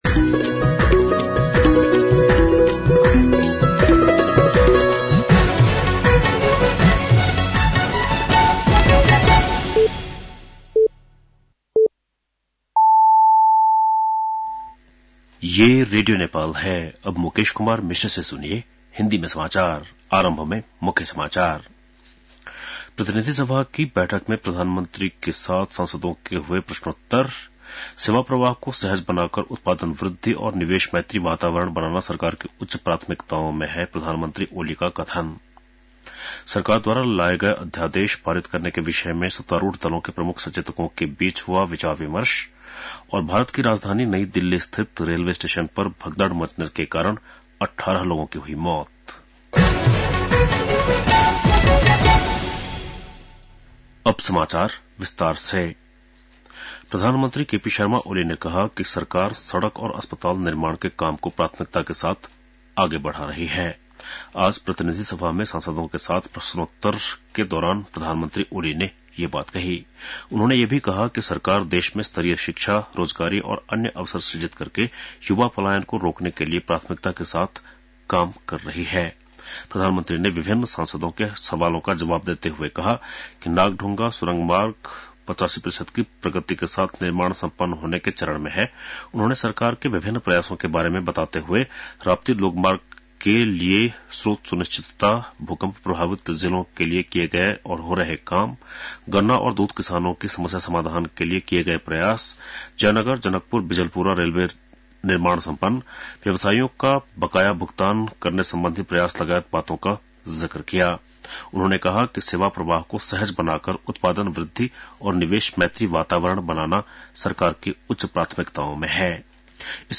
बेलुकी १० बजेको हिन्दी समाचार : ५ फागुन , २०८१